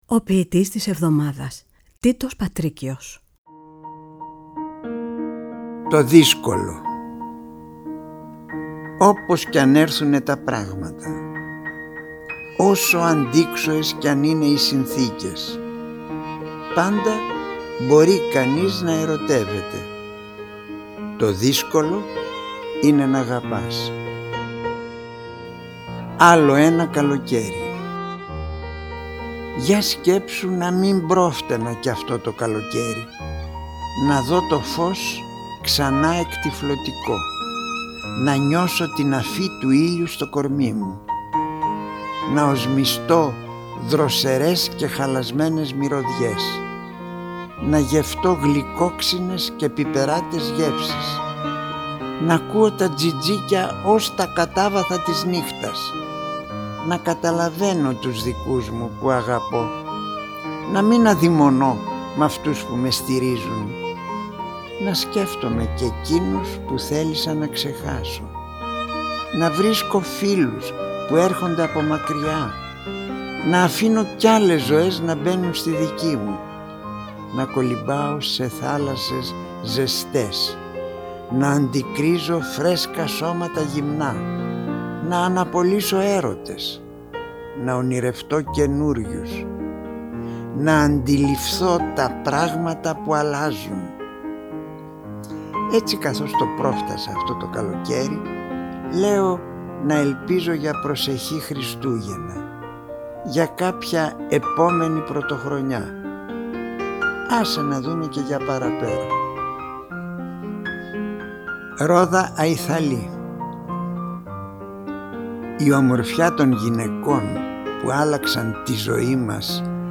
Η ΦΩΝΗ ΤΗΣ ΕΛΛΑΔΑΣ, το ραδιόφωνο της ΕΡΤ που απευθύνεται στους Έλληνες όπου γης, με όχημα τη διάδοση, τη στήριξη, και την προβολή της ελληνικής γλώσσας και του ελληνικού πολιτισμού, εντάσσει και πάλι στο πρόγραμμά της τα αφιερωματικά δίλεπτα ποίησης με τίτλο «Ο ποιητής της εβδομάδας».
Οι ίδιοι οι ποιητές, καθώς και αγαπημένοι ηθοποιοί επιμελούνται τις ραδιοφωνικές ερμηνείες. Παράλληλα τα ποιήματα «ντύνονται» με πρωτότυπη μουσική, που συνθέτουν και παίζουν στο στούντιο της Ελληνικής Ραδιοφωνίας οι μουσικοί της Ορχήστρας της ΕΡΤ, καθώς και με μουσικά κομμάτια αγαπημένων δημιουργών.
Παρουσίαση: Τίτος Πατρίκιος